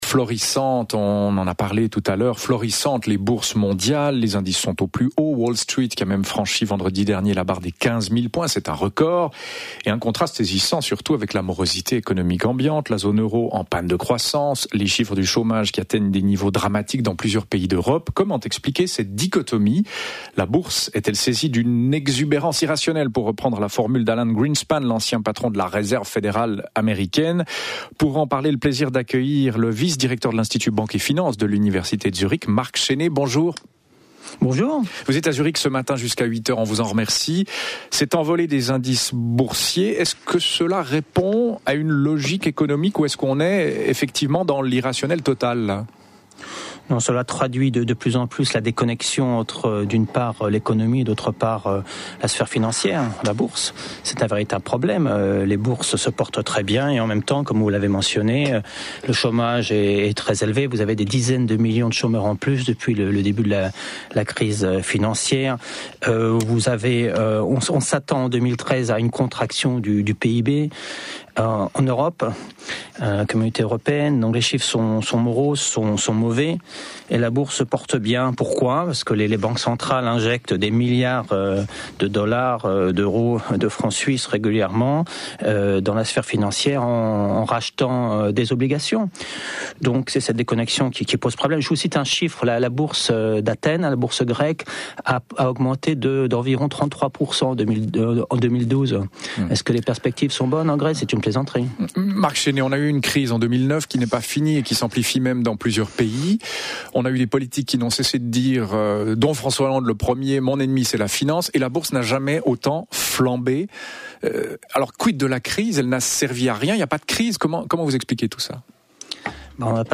l’invité du Journal du matin de la radio publique romande RTS. Le thème: des bourses mondiales florissantes, des indices au plus haut, et un contraste saisissant avec la morosité économique ambiante. Comment expliquer cette dichotomie?